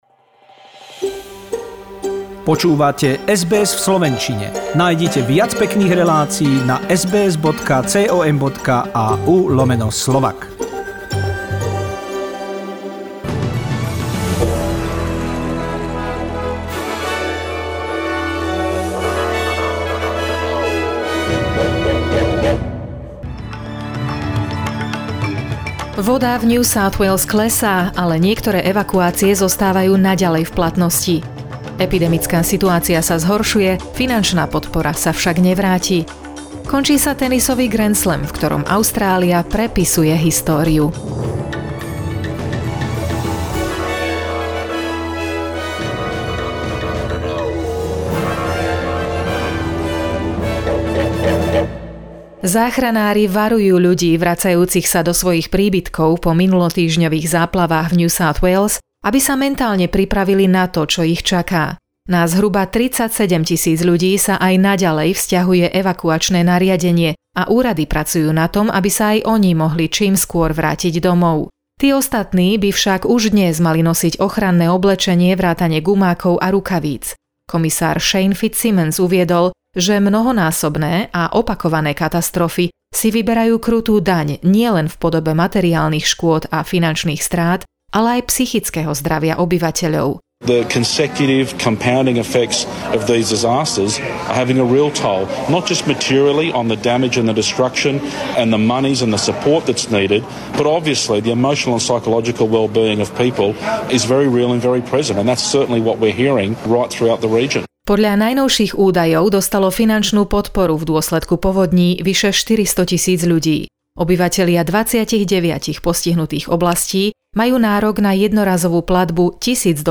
SBS NEWS v slovenčine